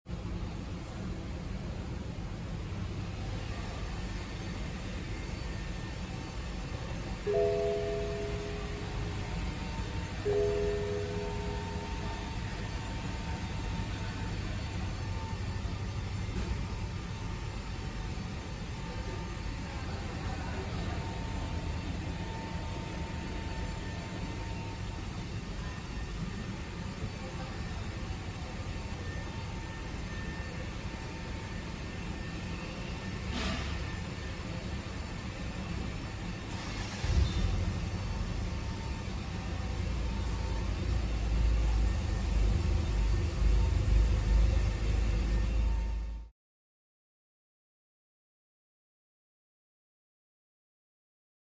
BMW 520i N20 XHP sound effects free download
-Rev-Matched downshifting (Throttle-Blip)